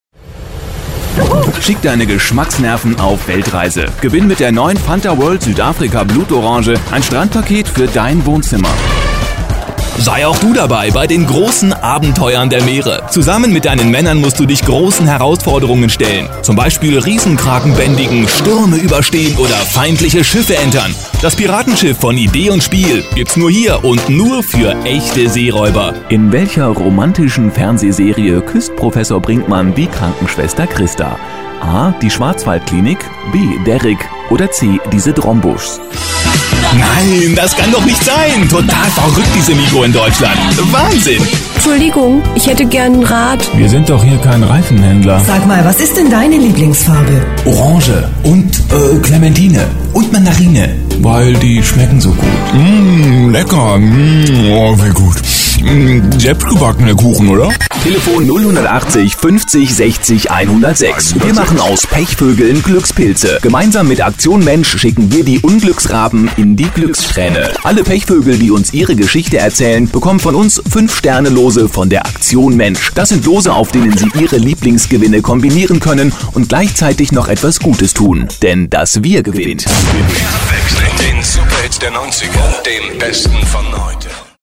Kein Dialekt
Sprechprobe: eLearning (Muttersprache):